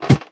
ladder4.ogg